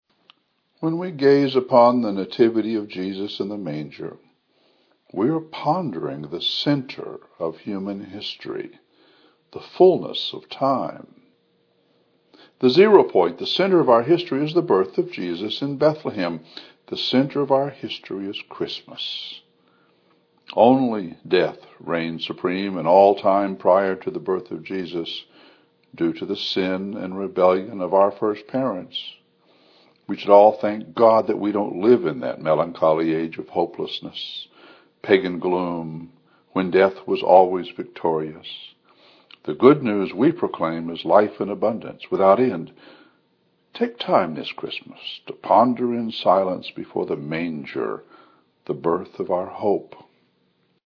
Filed Under: One Minute Homilies